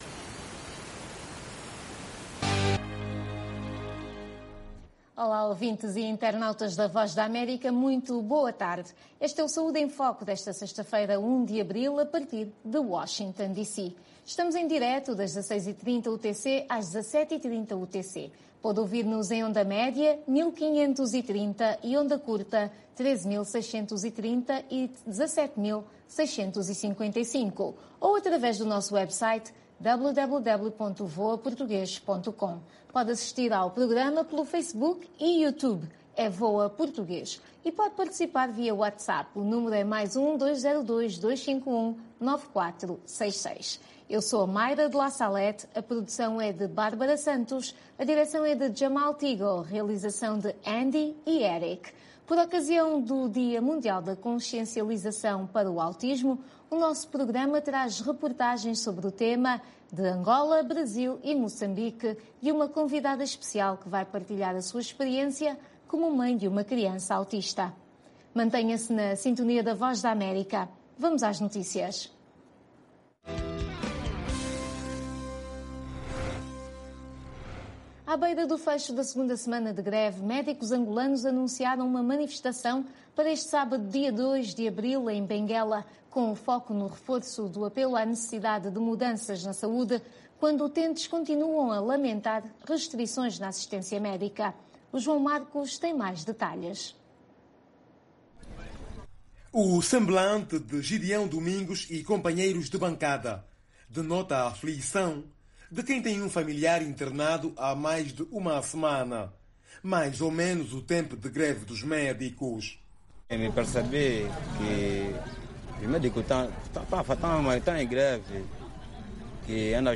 O programa Saúde em Foco é transmitido às sextas-feiras às 16h30 UTC. Todas as semanas falamos sobre saúde, dos Estados Unidos da América para o mundo inteiro, com convidados especiais no campo social e da saúde.